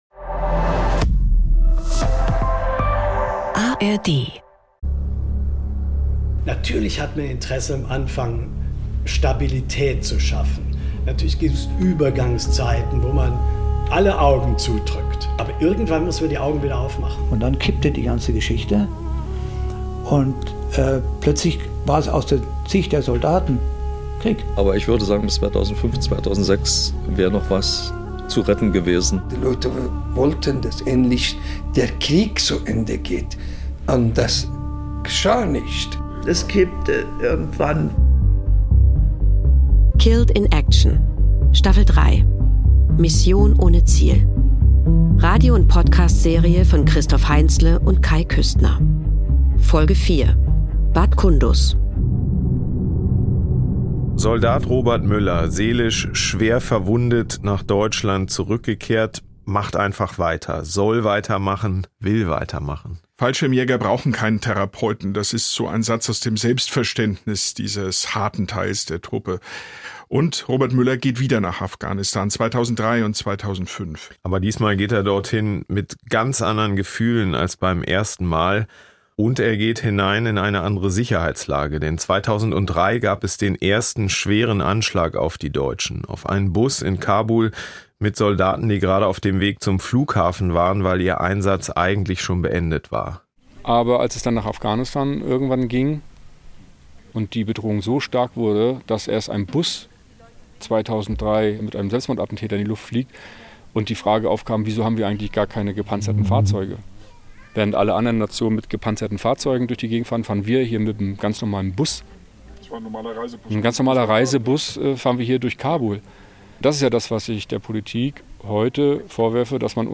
Radio- und Podcastserie